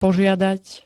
Zvukové nahrávky niektorých slov
a4g2-poziadat.spx